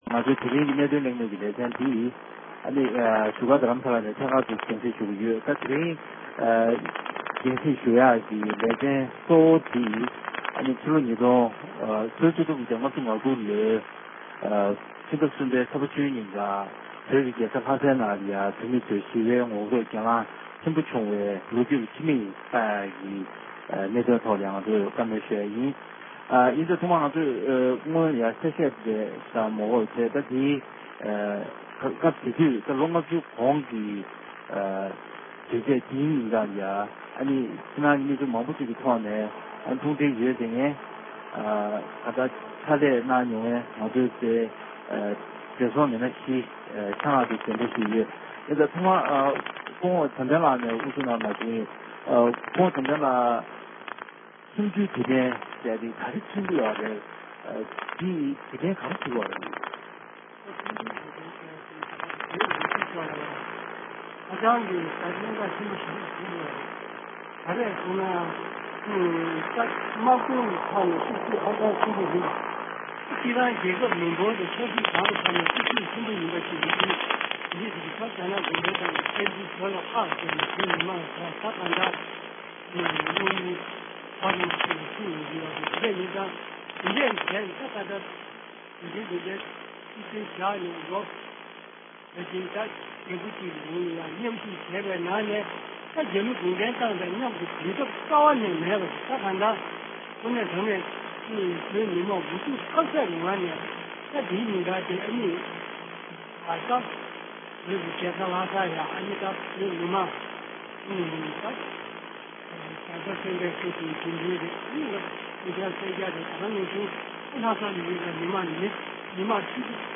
བོད་ཀྱི་རྒྱལ་ས་ལྷའི་ནང་བོད་མིས་སྒེར་ལངས་ཀྱི་ལས་འགུལ་དངོས་སུ་གཟིགས་མྱོང་མཁན་མི་སྣའི་ལྷན་གླེང་བ།